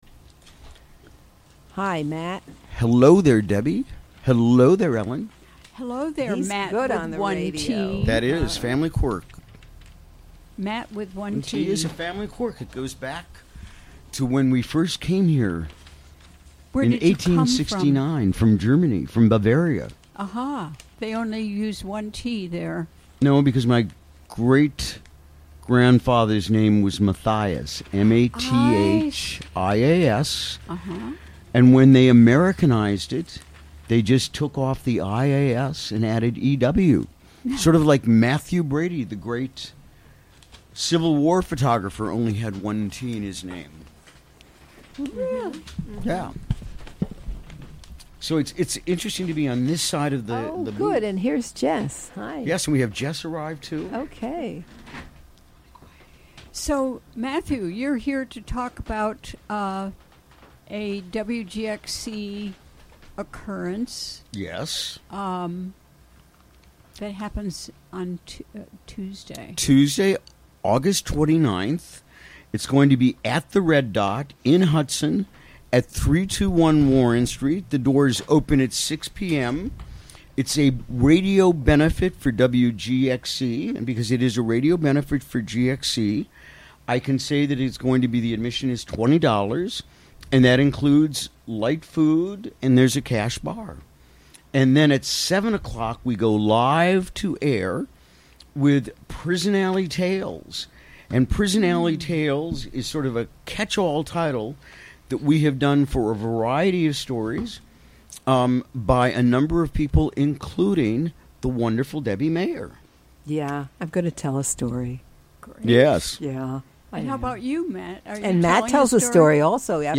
Recorded during the WGXC Afternoon Show on August 24, 2017.